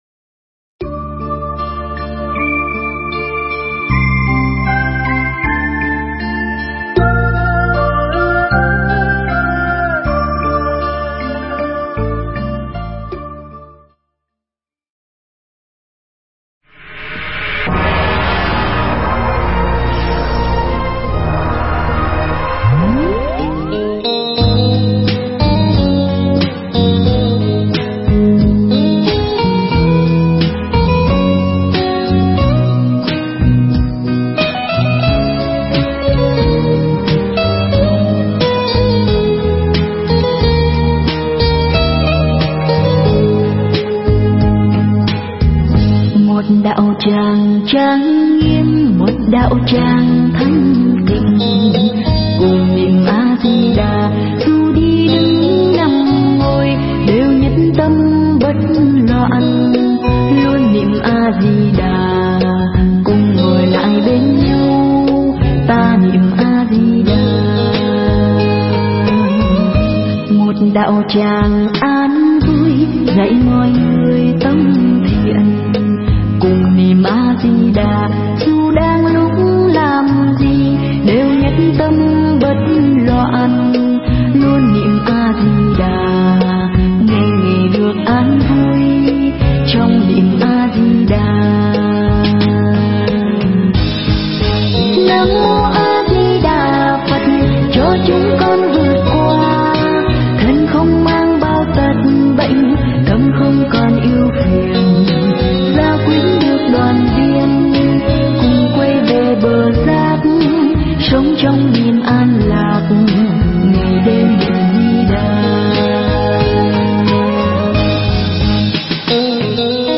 Nghe Mp3 thuyết pháp Kinh Nhất Dạ Hiền Giả